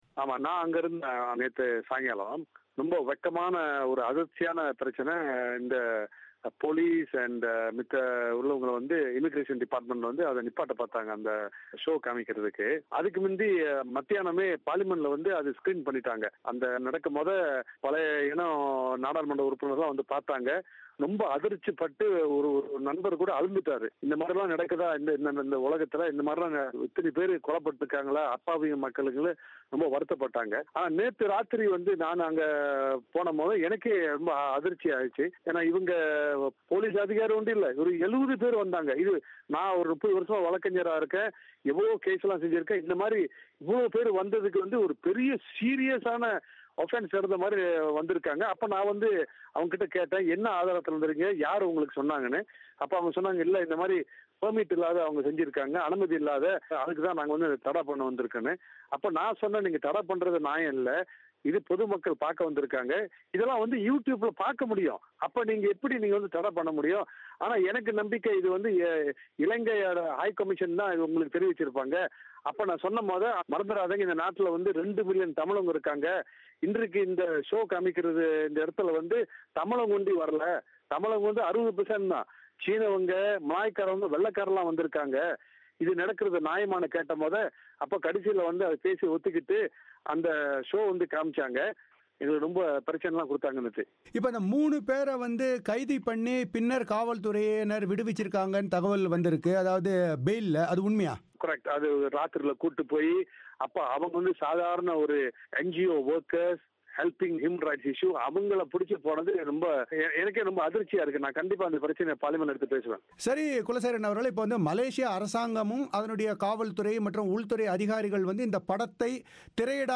இலங்கையின் இறுதிகட்டப் போரில் இடம்பெற்றதாகக் கூறப்படும் மனித உரிமை மீறல்களை மையப்படுத்தி எடுக்கப்பட்ட ஆவணப் படம் திரையிடப்படுவதை தடுக்க மேற்கொள்ளப்பட்ட முயற்சிகள் குறித்து ஜனநாயக செயல்கட்சியின் நாடாளுமன்ற உறுப்பினர் குலசேகரன் பேட்டி